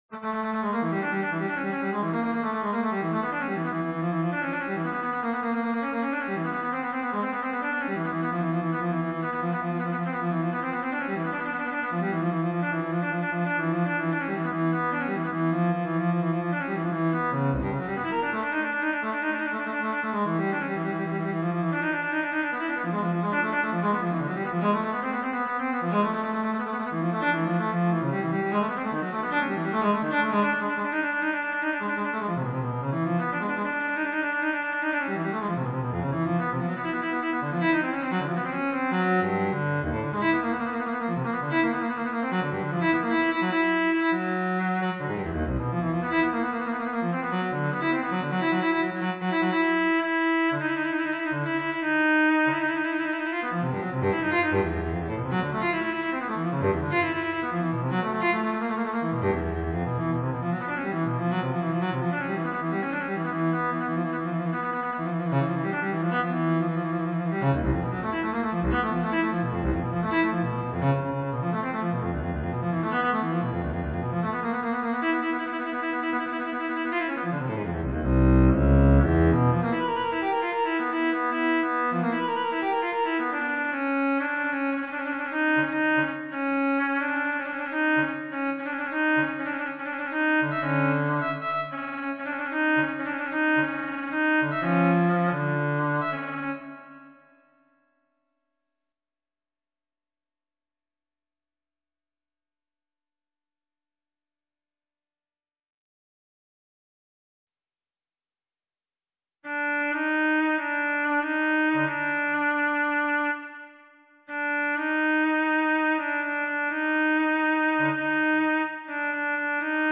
m4—for cello solo  a set of four
The first frame of m4 featured here introduces the set with a wildly fast and rugged
stream of musical figures which unfolds within a non-stop, forward-driving tempo.
a stream of serpentine movement, moving in unexpected ways over the entire instrument.
an elegy . . .
a dramatic duo . . .
| NEW: download MP3 of a performance model of m4: frames I-IV [c. 9' 10" 2.1 Mb]